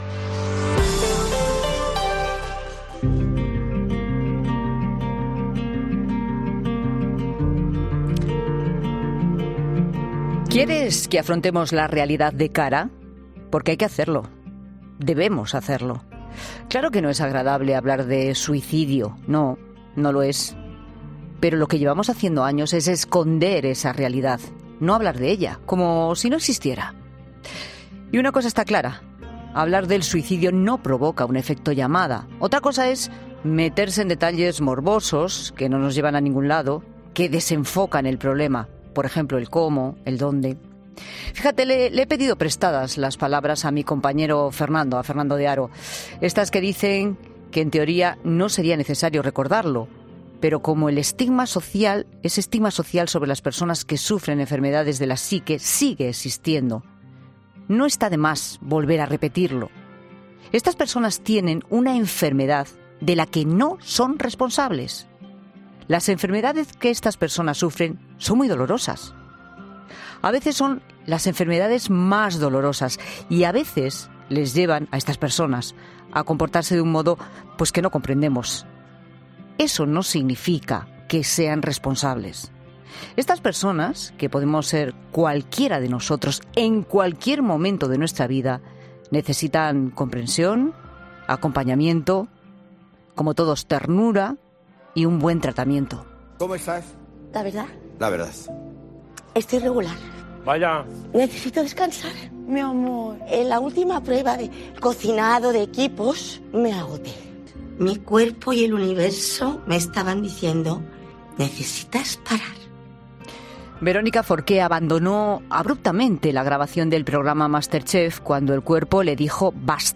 'La Tarde' de COPE ha contactado con una persona que estuvo en esta situación para conocer de primera mano cómo viven estos individuos este problema